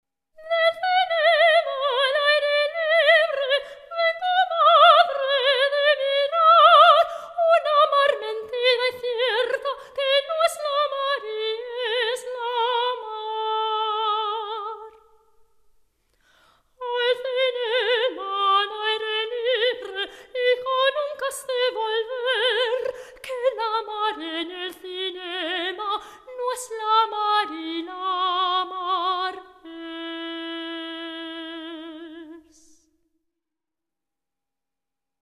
Lieder und Arien
(a capella)